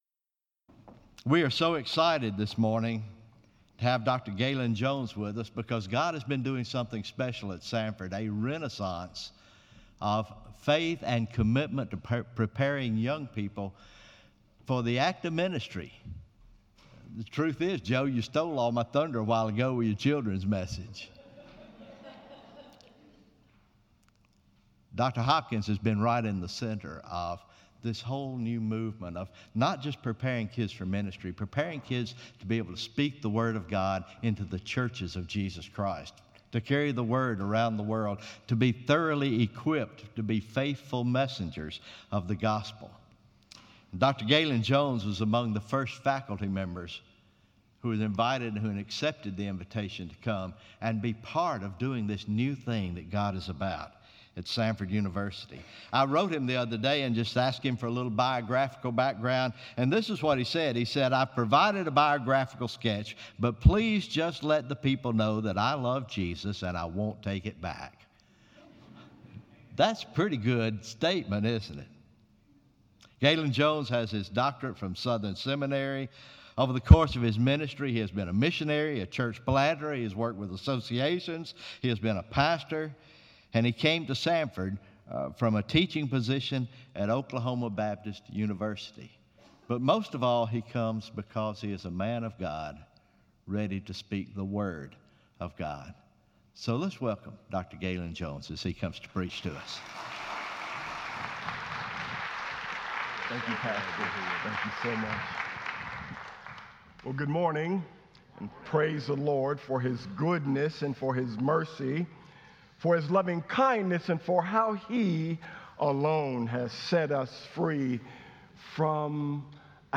Morning Worship